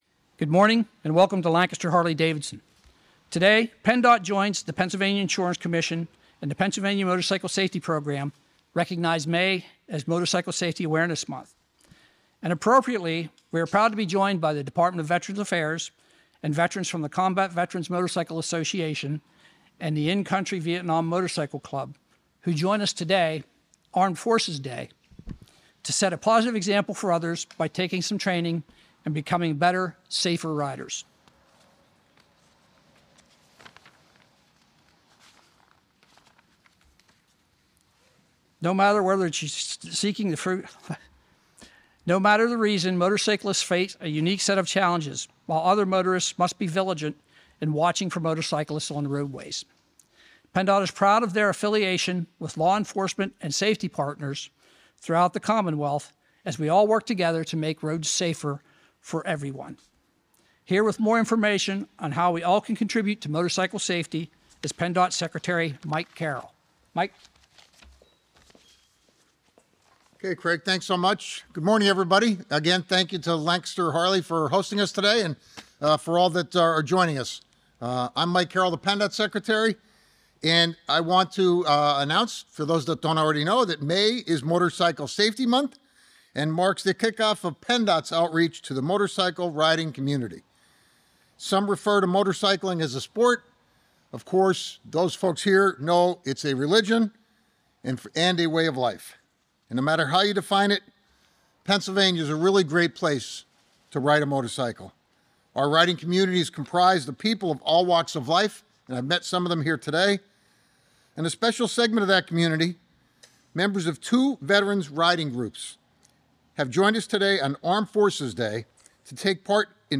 At an event in Lancaster, members of the Shapiro Administration highlighted the Pennsylvania Department of Transportation's Pennsylvania Motorcycle Safety Program (PAMSP).